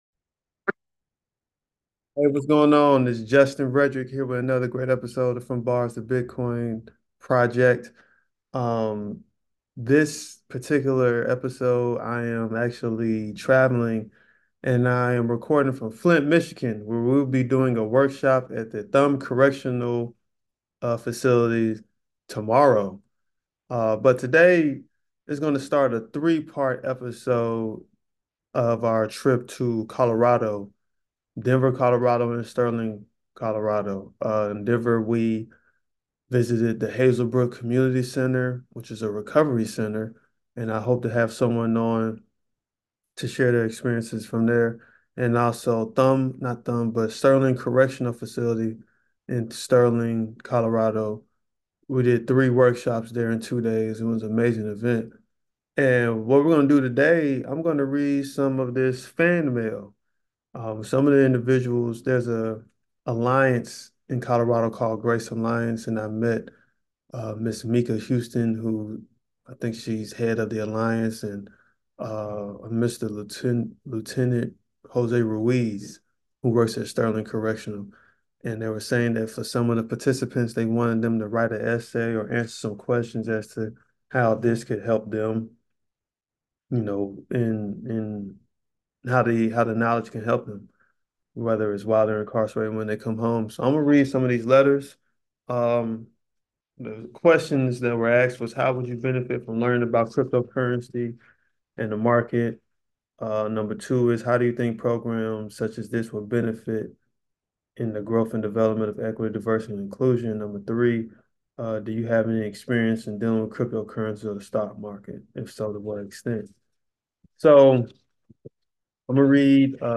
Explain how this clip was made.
In this episode you hear letters written by the residents of Sterling Correctional Facility give their thoughts on bitcoin, financial literacy, and how they plan to use this workshop to be successful upon release.